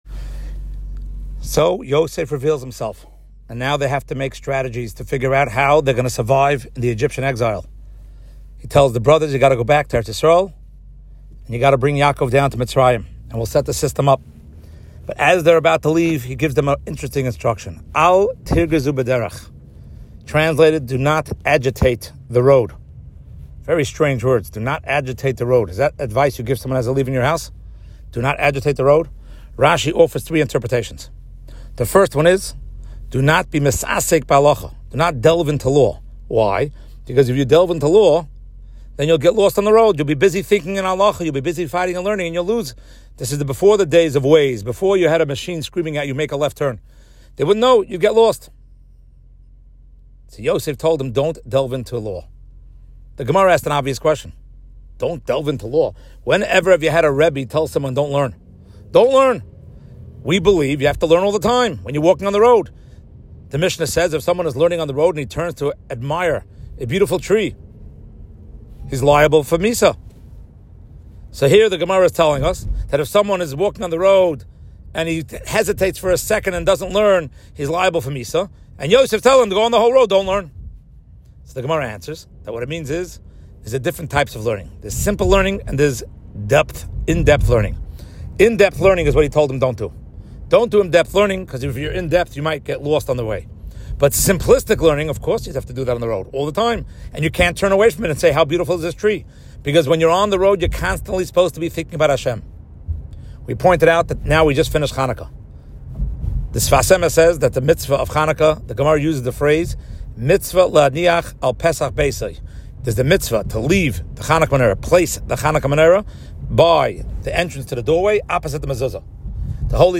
Short Shiur